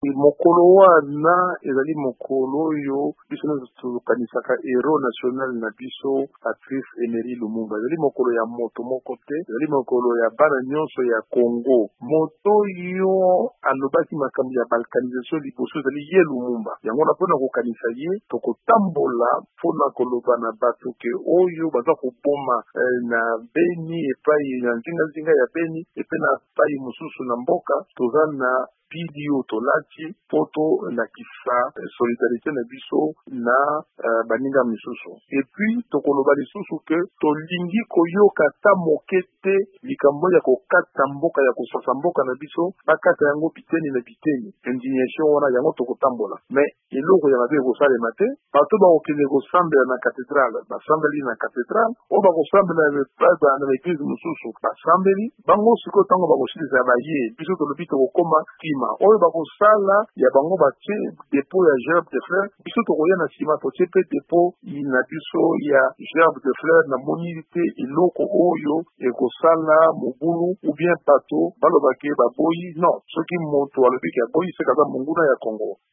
Martin Fayulu alobi esengeli kotambola le 17 janvier mpo ezali mokolo ya kopesa losako na Patrice Emery Lumumba moto ya yambo aboyaki balkanisation ya RDC. VOA Lingala ebengaki Martin Fayulu mpo na kolimbola malabo apesaki lobi loleki na bokutani na bapanzi sango.